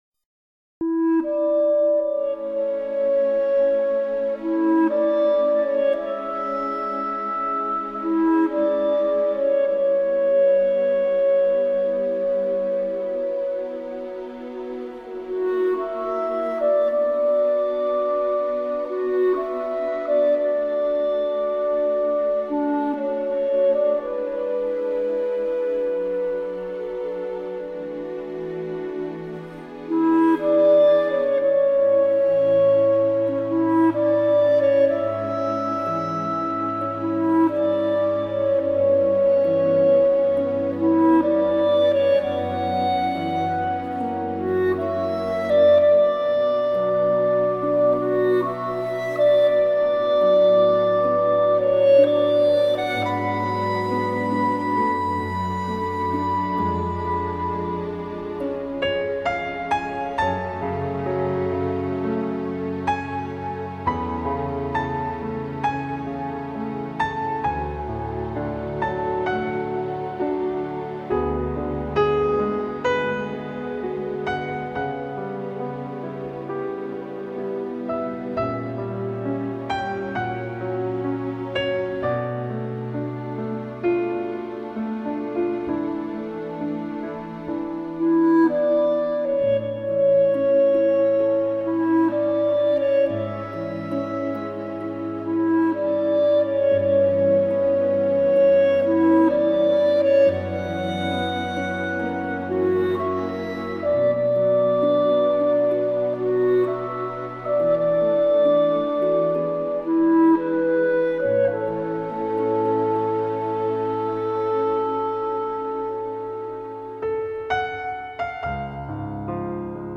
类　别： NewAge
清澈而没有过多的粘稠，温柔却又溪水长流。
更加入柔情的小提琴和单簧管伴奏，听起来非常温暖和舒适。
在清幽的音乐氛围里，清澈的钢琴音色，恰如其分地妆点出绮丽的光影，
融合大提琴与小提琴婉约动人的低诉，形成比例完美的弦乐合奏，